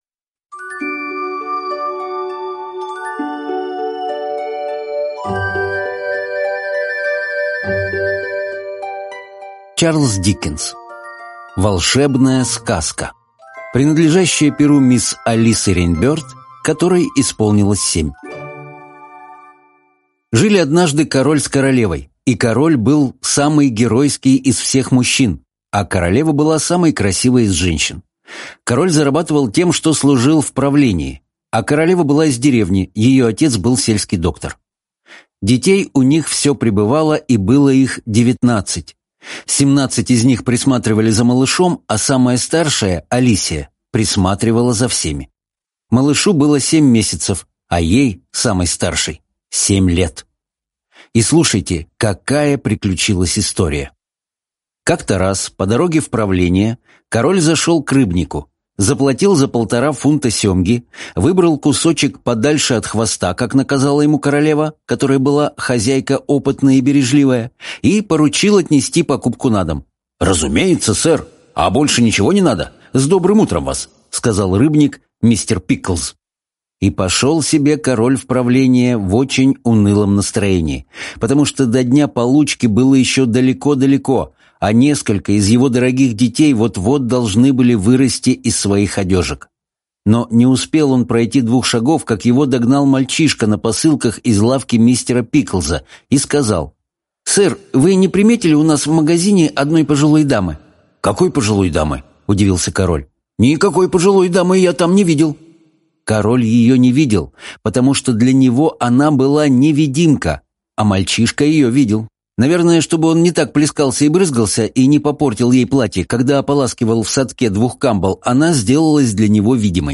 Волшебная сказка — слушать аудиосказку Чарльз Диккенс бесплатно онлайн